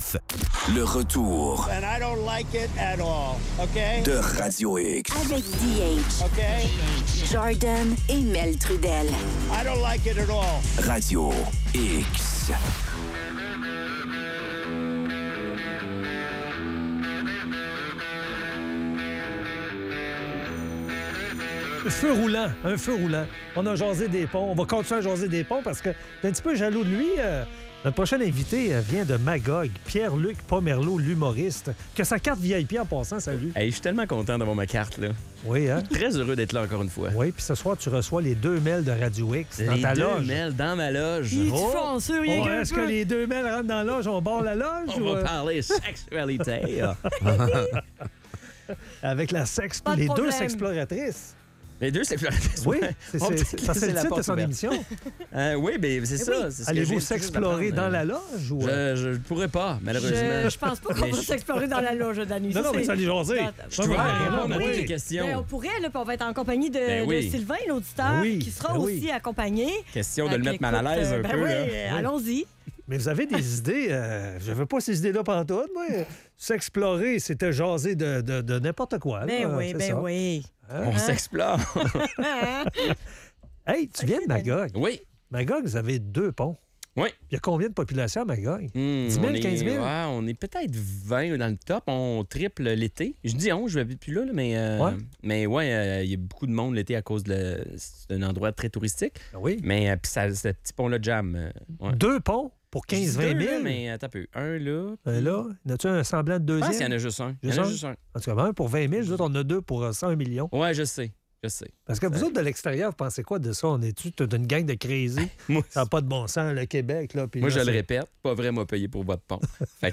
en studio!